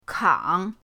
kang3.mp3